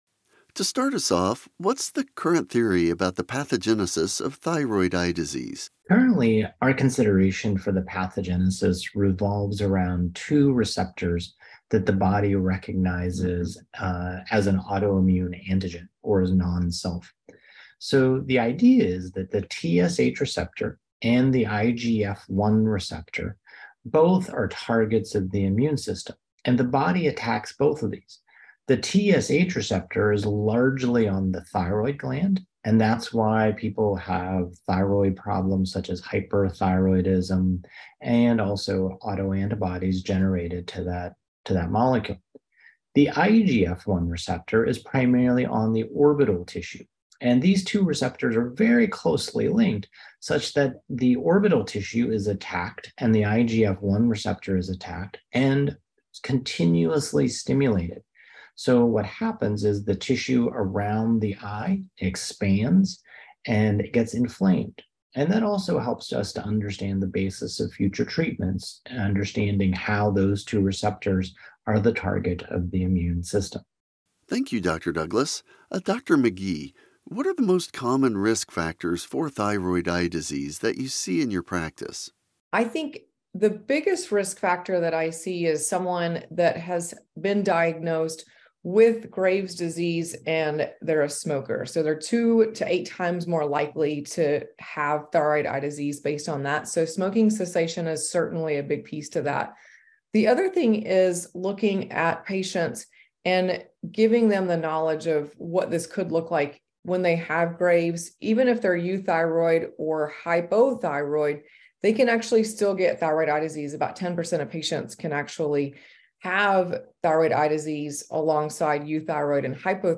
In this episode, four expert faculty talk about the importance of early diagnosis and treatment of thyroid eye disease. They discuss the differential diagnosis, screening tests, and the need for multidisciplinary management for these patients.